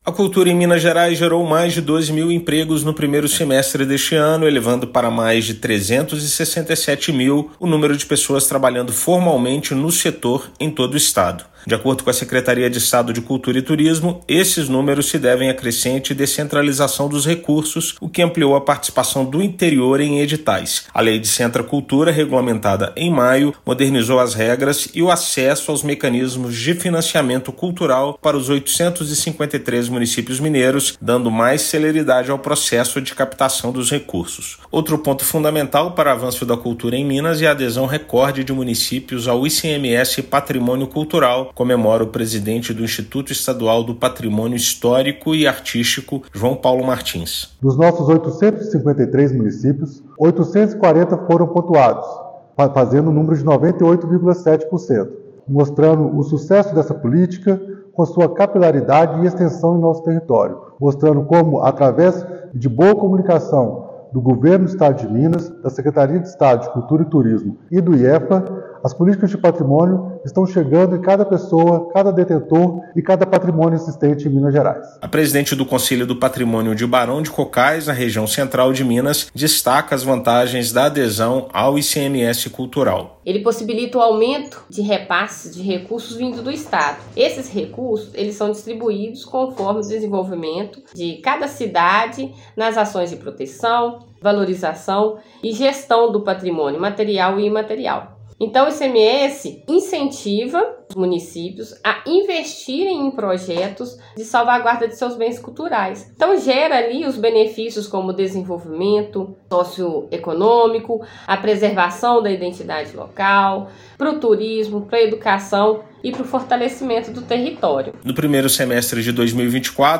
Recorde do ICMS Patrimônio Cultural e regulamentação da Lei Descentra Cultura, em maio, também são destaques do setor em 2024. Ouça matéria de rádio.